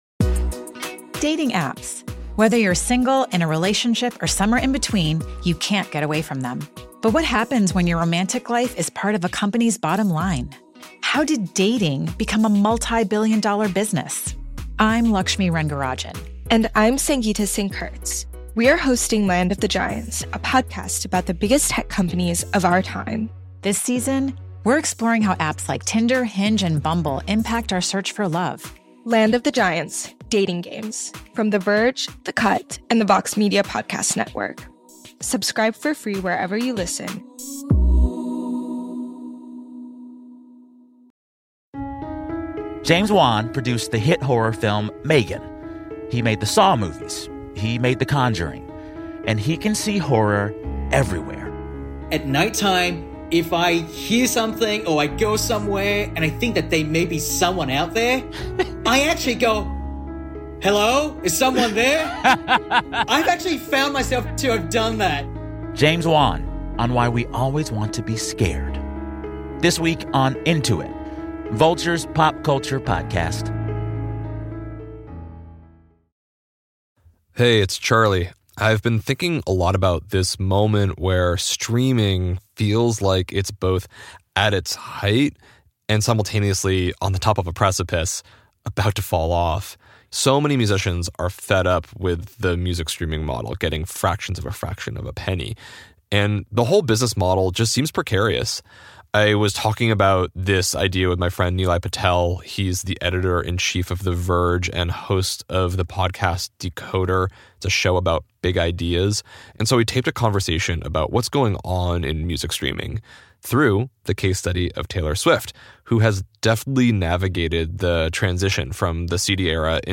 And they taped a conversation about what’s next for streaming through the case study of Taylor Swift who has deftly navigated the transition from CDs to streaming, and whose era tour may mark the end of an era in music.